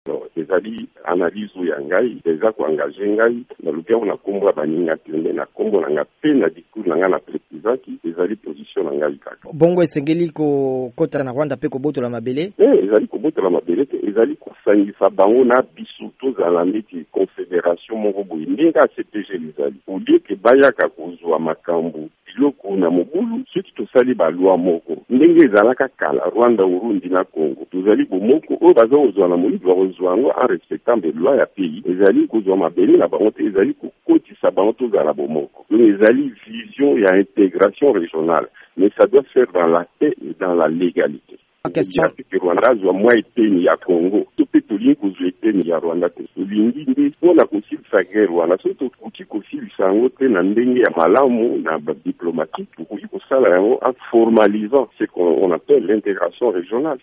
Muzito asengi kosangisa Rwanda kati na RDC mpo bitumba bisila (Interview)
VOA Lingala epesaki maloba na Adoplhe Muzito.